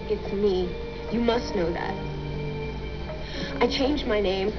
These are the sound files of Sandrine Holt's voice from her TV series.